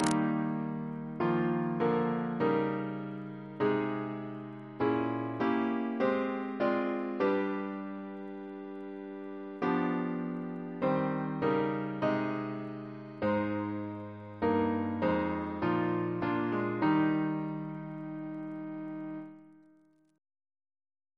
Double chant in E♭ Composer: Sir Ivor Algernon Atkins (1869-1953), Organist of Worcestor Cathedral Reference psalters: ACP: 97